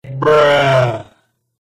Bruh Meme But It's Slowed Down 2x